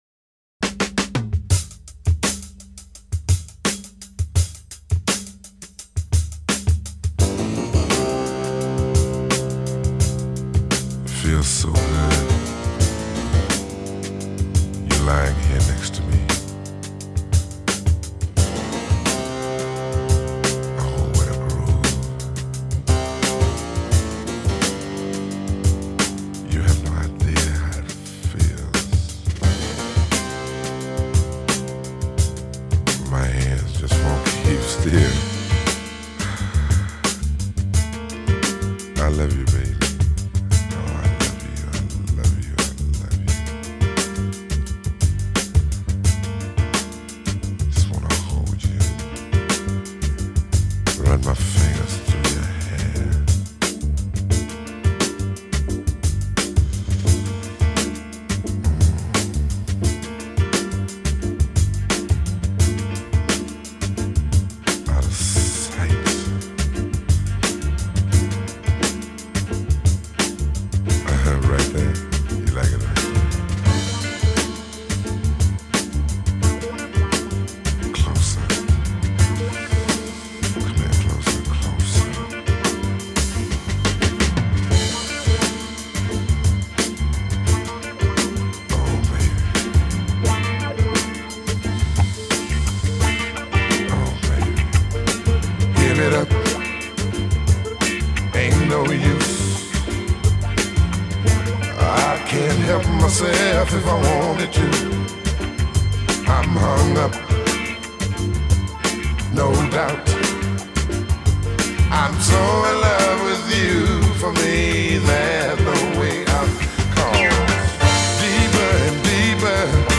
глубокий баритон и откровенно соблазнительная лирика.